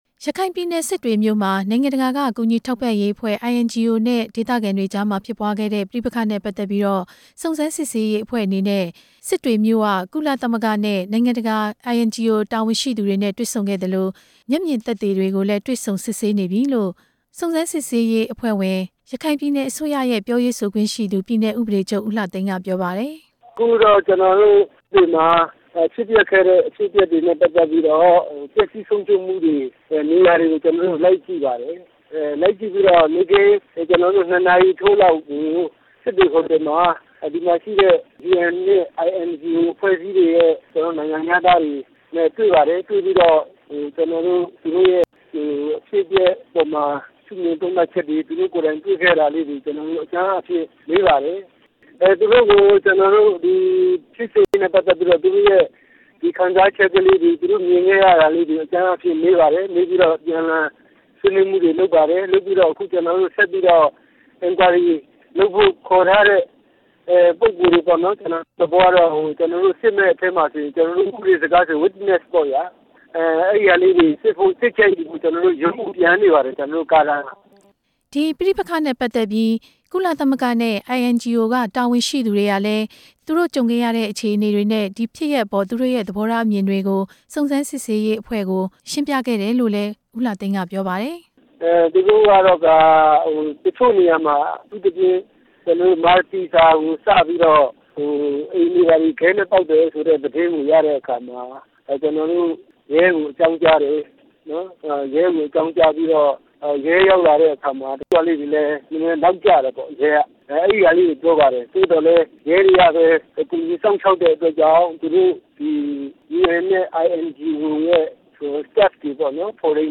စစ်တွေမြို့ ပဋိပက္ခ စစ်ဆေးတွေ့ရှိမှုများအပေါ် မေးမြန်းချက်